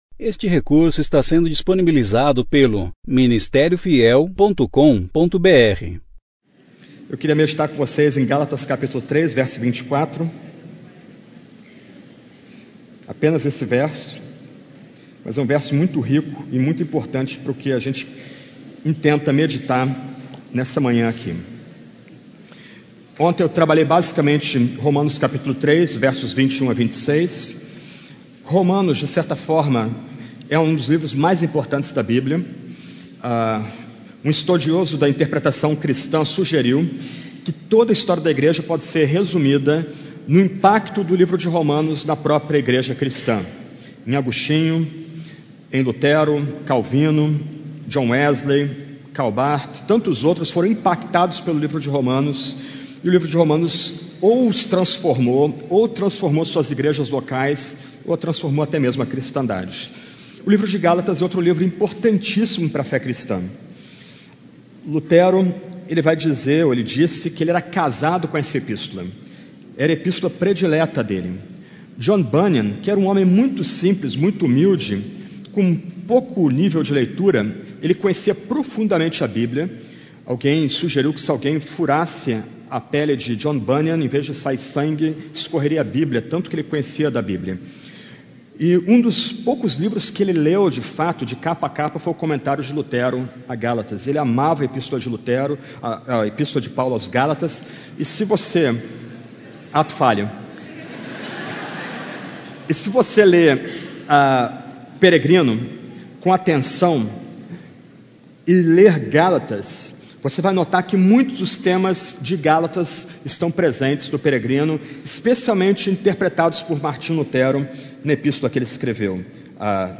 Conferência: 15ª Conferência Fiel para Jovens – Brasil Tema: 5 Solas Ano: 2017 Mensagem: Sola Gratia